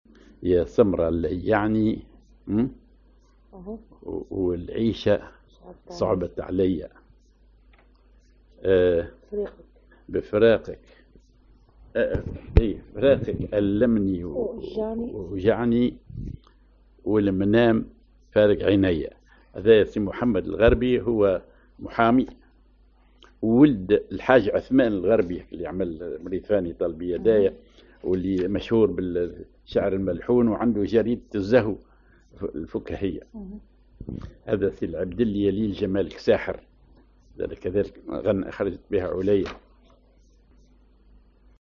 genre أغنية